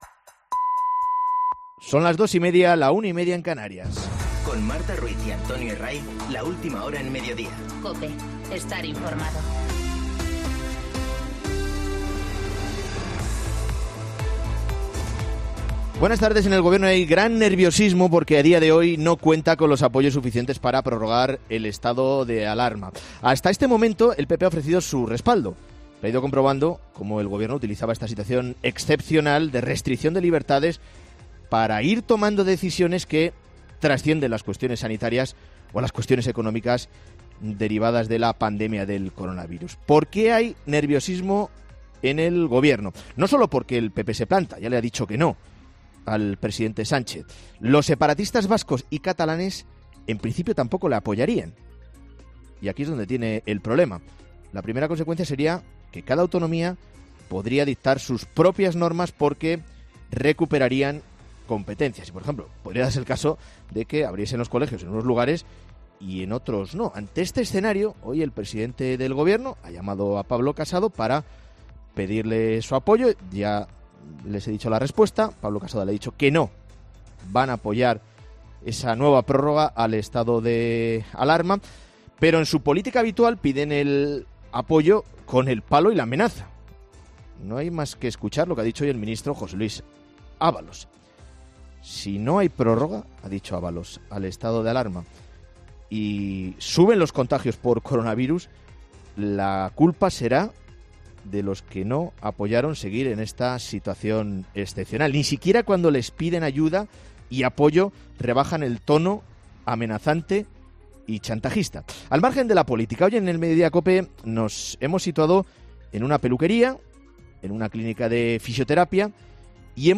Monólogo de